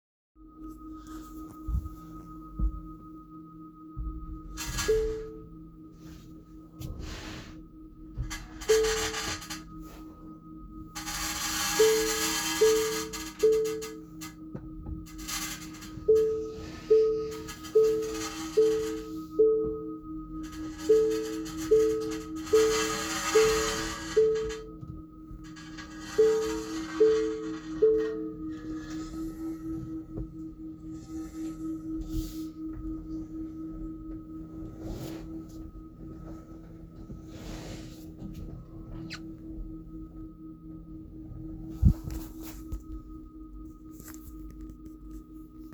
Grzechoczący dźwięk / tarcie blachy w kole Tesli? To mogą być kamyczki [Forum Tesla Model Y]
Hałas, tarcie o blachę wewnątrz koła może mieć prozaiczne przyczyny
Tesla-Model-Y-tarcie-w-kole.mp3